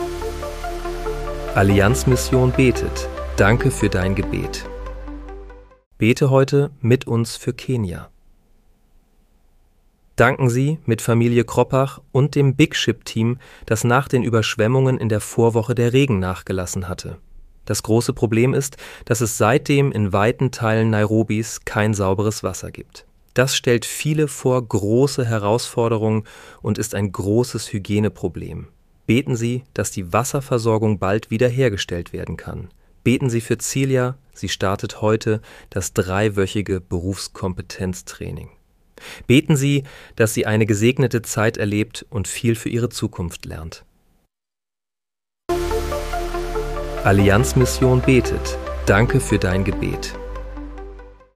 Bete am 17. März 2026 mit uns für Kenia. (KI-generiert mit der